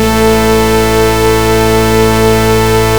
Big Fm Wave A3.wav